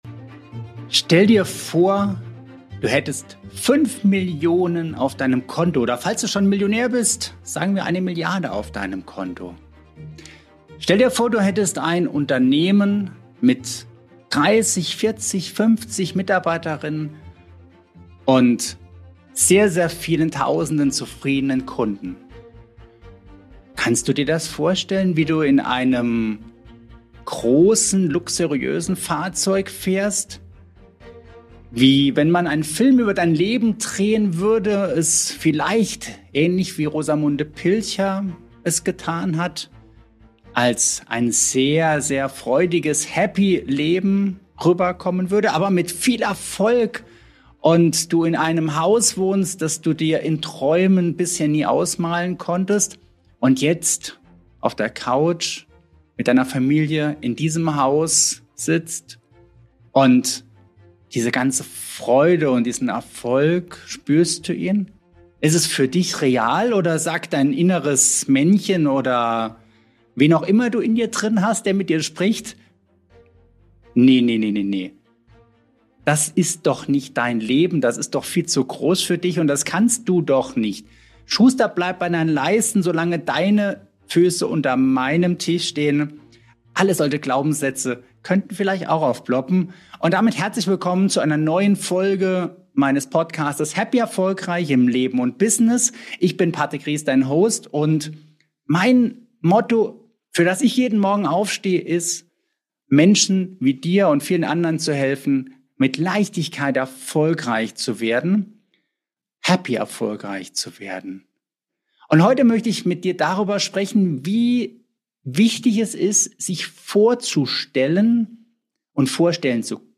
In dieser Solo-Folge von happy.erfolg.reich spreche ich